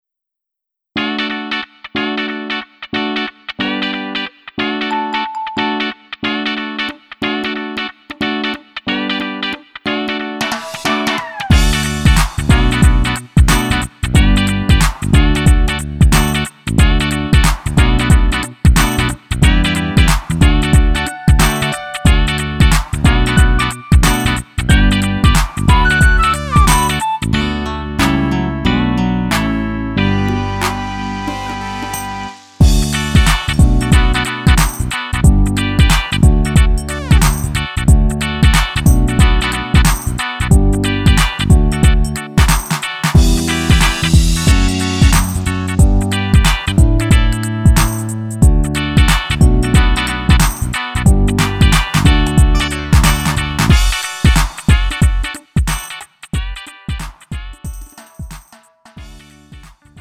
음정 원키 3:02
장르 가요 구분